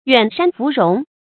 远山芙蓉 yuǎn shān fú róng
远山芙蓉发音